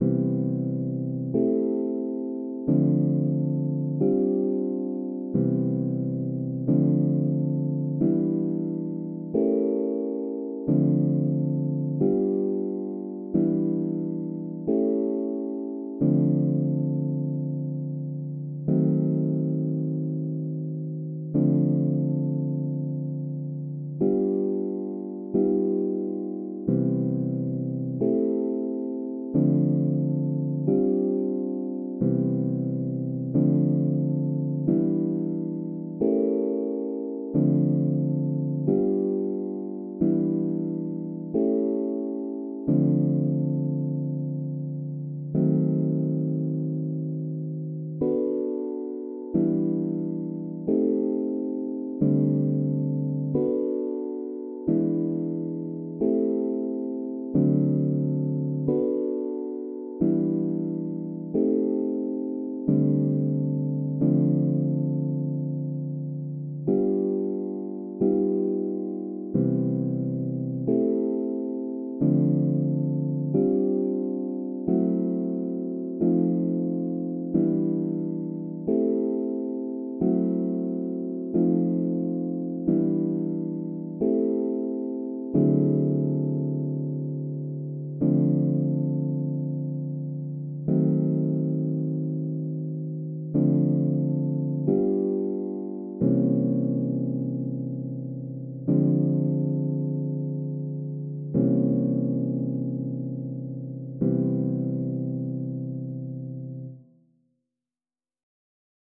Chords only audio is